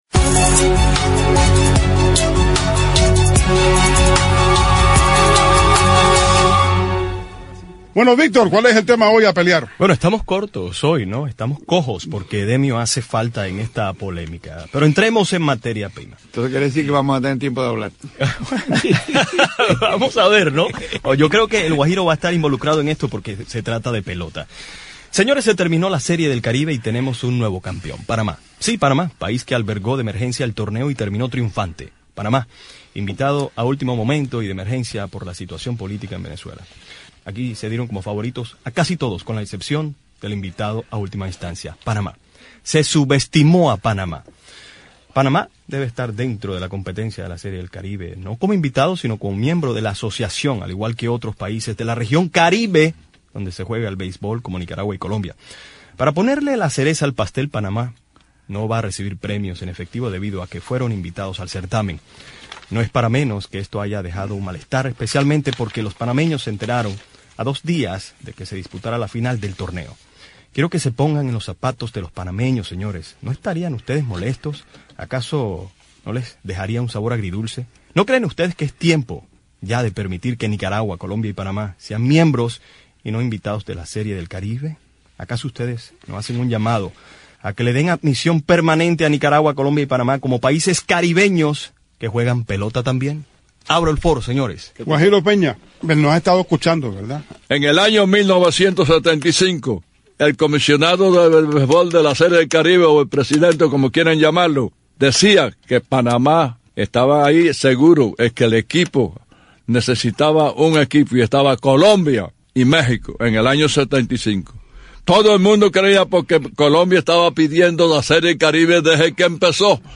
Recuerde la cita, de 7 p.m. a 8:30 p.m., por Radio Martí.